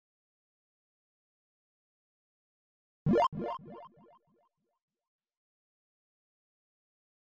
Refactor upgrade logic to include sound effects on purchases and upgrades, improving overall gameplay immersion. 2025-03-30 14:24:53 -04:00 175 KiB Raw History
upgrade.mp3.bak